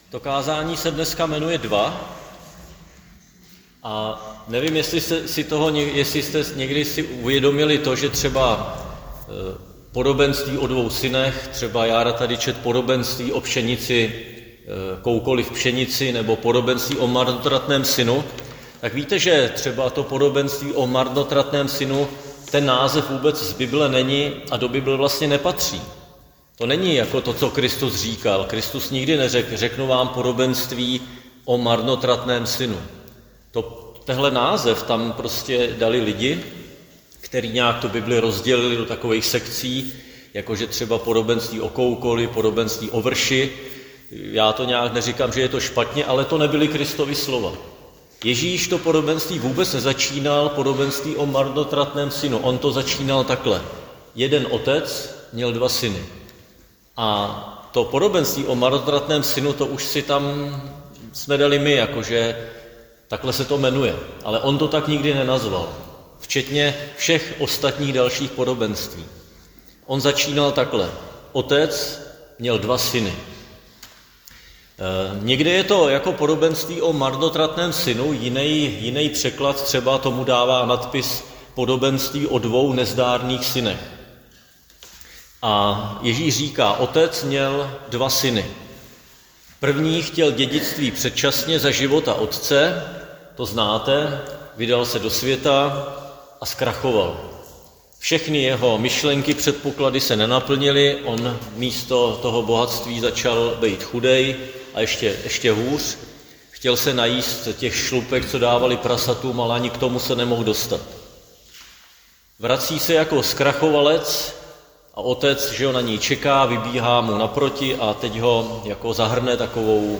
Křesťanské společenství Jičín - Kázání 9.3.2025